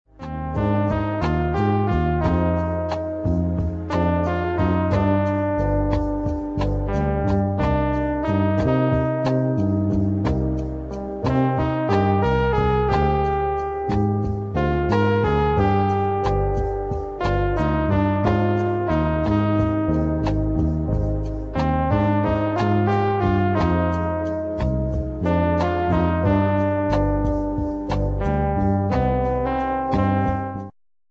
relaxed medium instr.